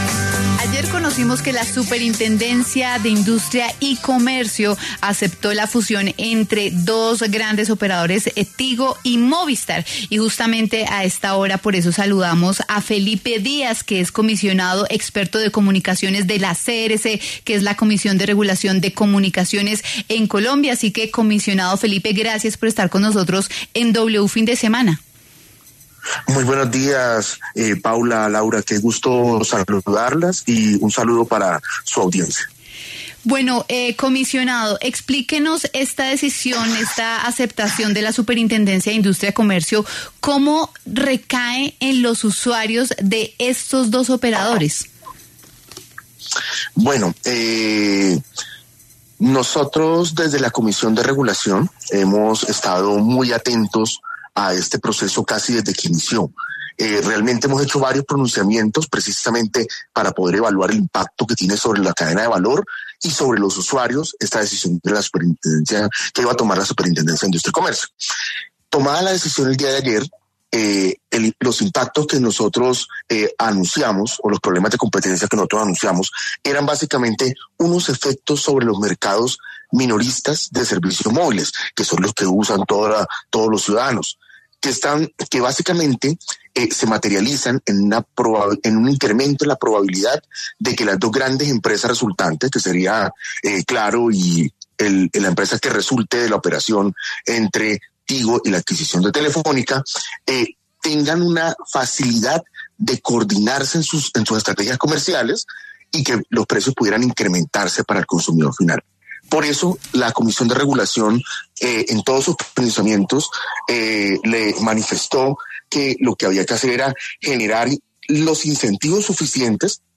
Felipe Díaz, comisionado experto de comunicaciones de la Comisión de Regulación de Comunicación (CRC), explicó, en los micrófonos de W Fin de Semana cómo recae en los usuarios de estos dos operadores dicha fusión.